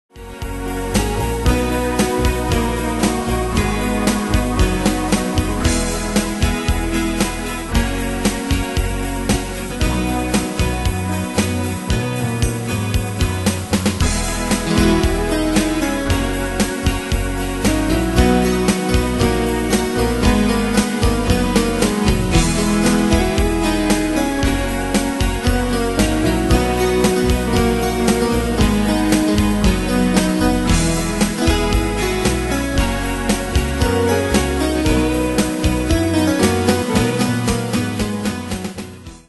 Style: PopAnglo Ane/Year: 2006 Tempo: 115 Durée/Time: 3.15
Danse/Dance: Continental Cat Id.
Pro Backing Tracks